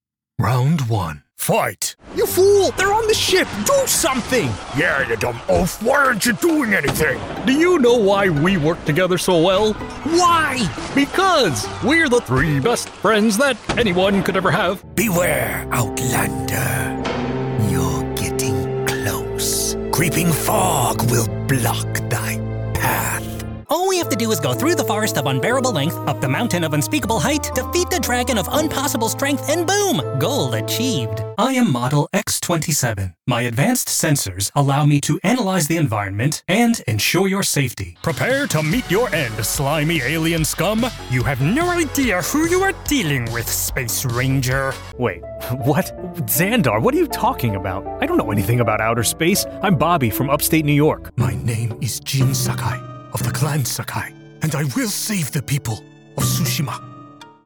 Male
Character / Cartoon
Character Demo-Animated, Gamin